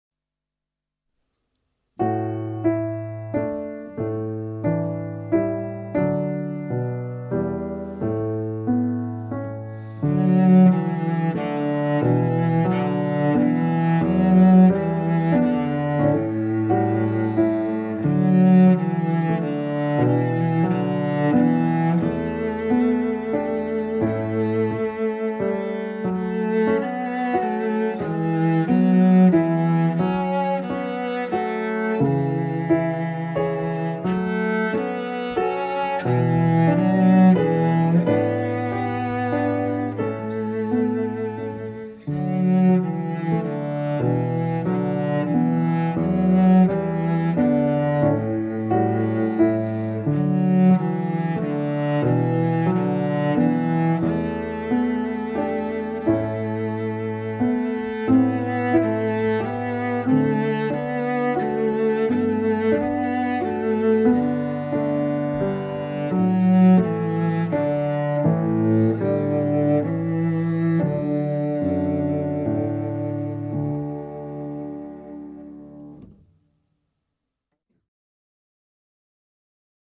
23 Clare's Song (Cello)